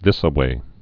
(thĭsə-wā)